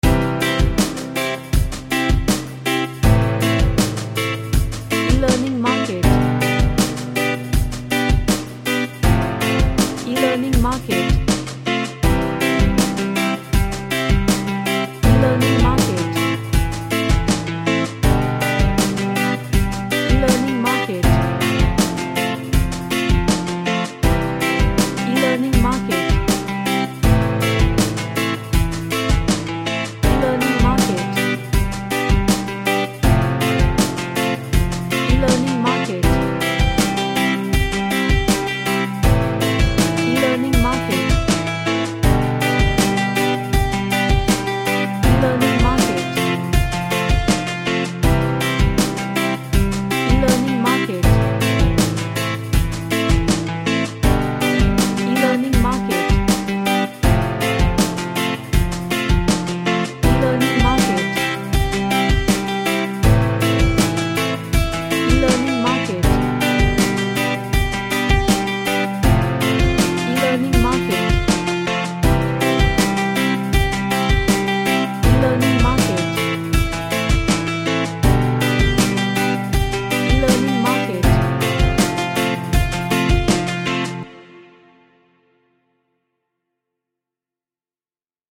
A Happy reggae track with playfull vibe.
Happy